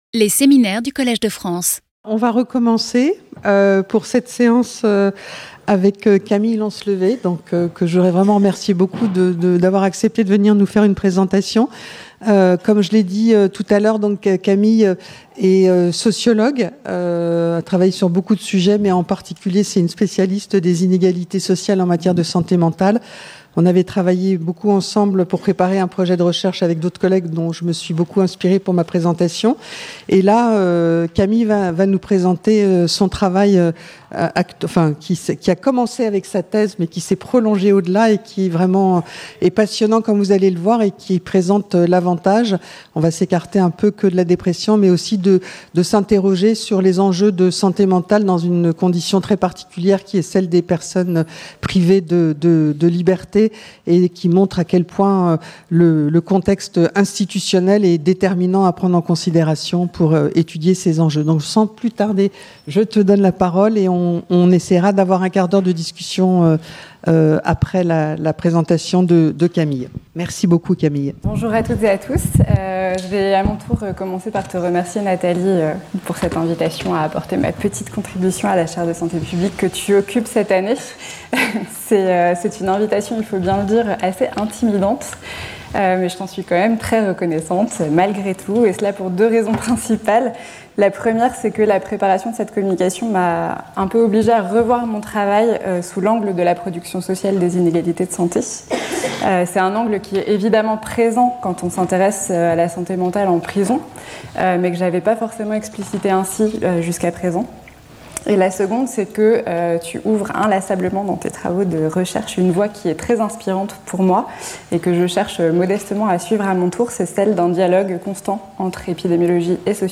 This talk, based on empirical data produced during a multi-sited ethnography over several years (2011-2022), proposes to question the intersecting evolutions of public psychiatry and criminal